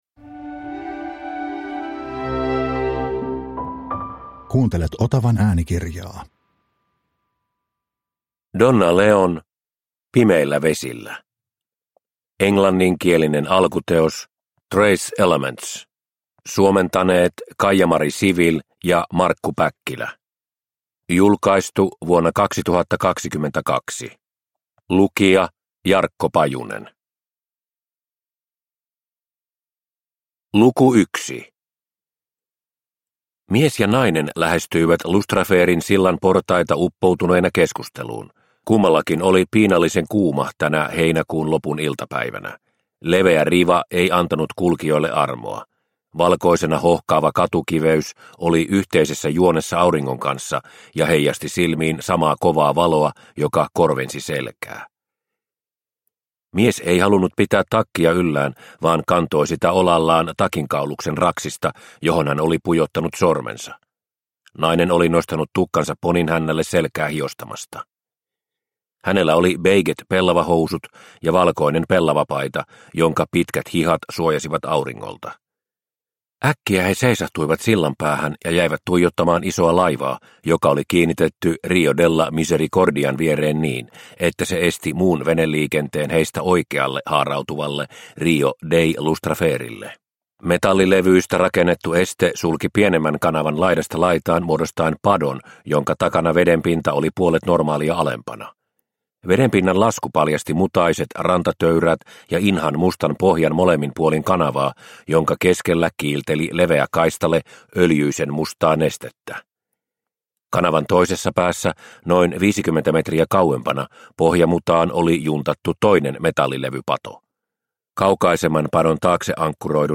Pimeillä vesillä – Ljudbok – Laddas ner